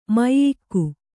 ♪ maiyikku